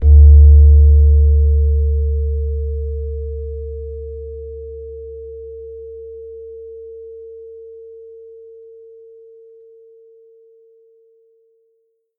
metallophone.mp3